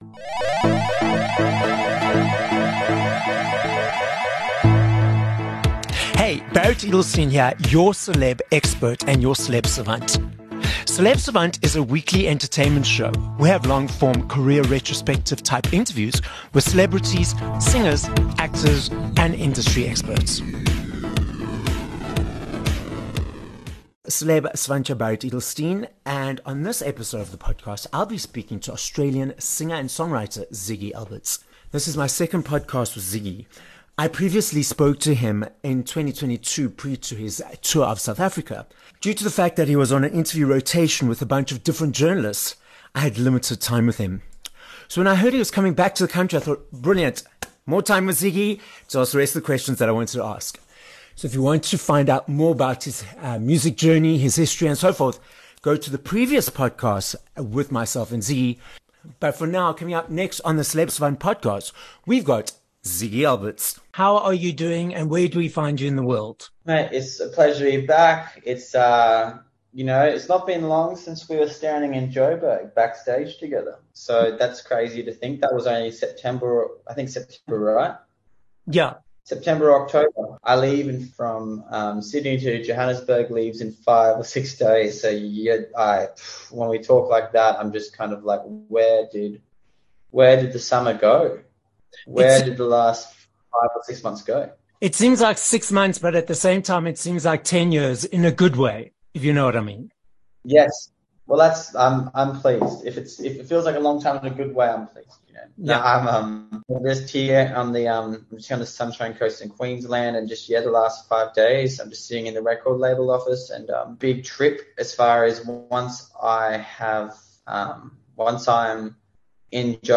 30 Mar Interview with Ziggy Alberts